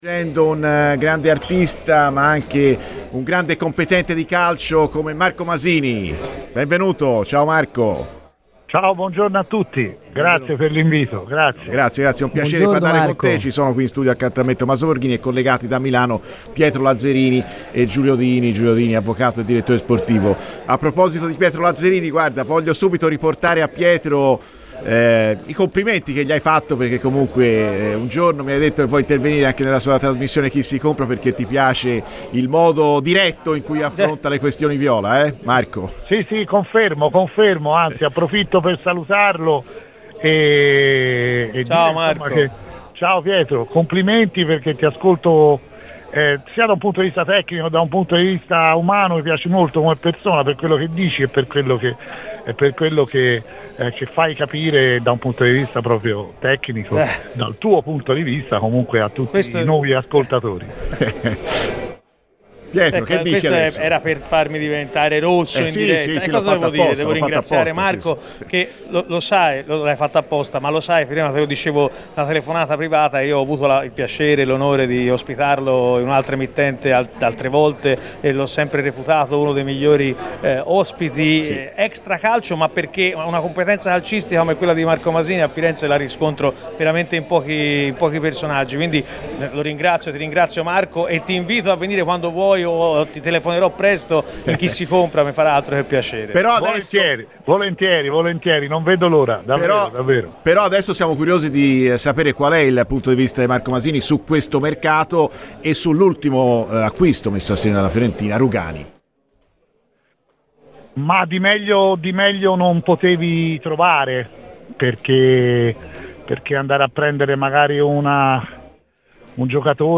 Ascolta il resto dell'intervista a Masini nel nostro podcast!